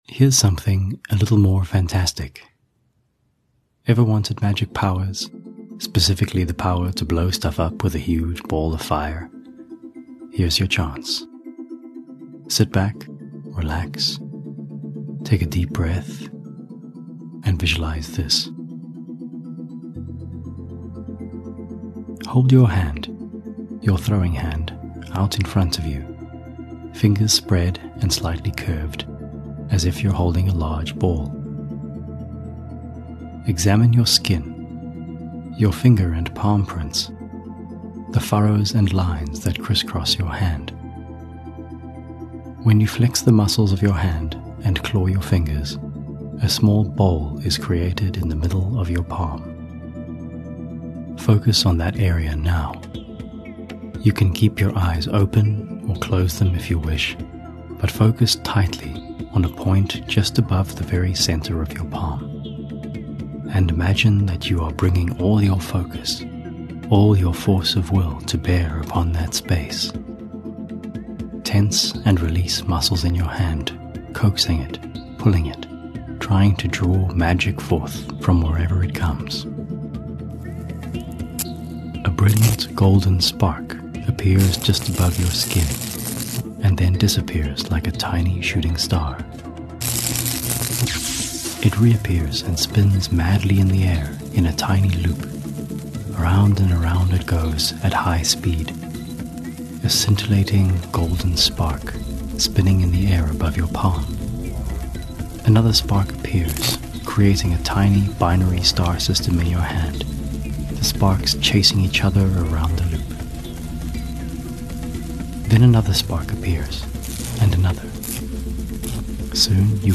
14 Aug 2020 Cast a powerful fireball spell in this immersive audio experience
But what would it actually feel like to create fire from nothing, shape it into a missile, and obliterate anything from orcs to castles, to double-parked SUVs? I'm enjoying the heck out of doing more sound engineering on these visualisations.